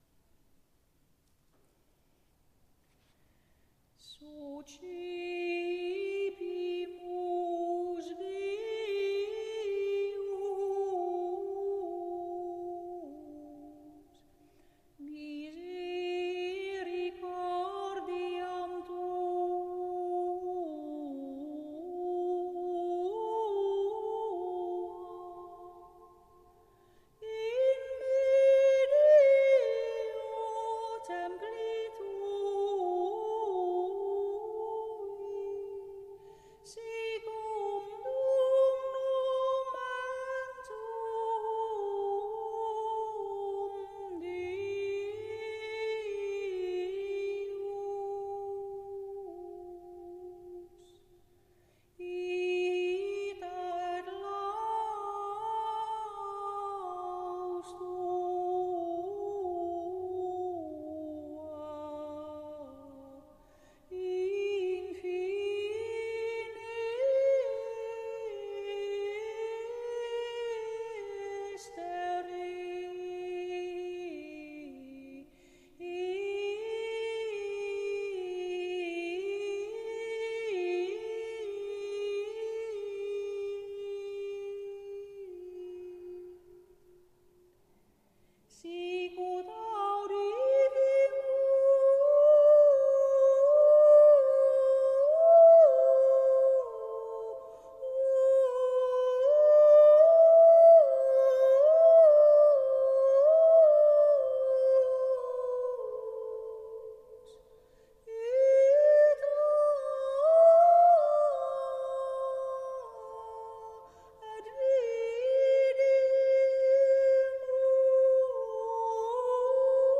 Suscepimus (Ps. 47, 10.11.9), graduale  WMP   RealPlayer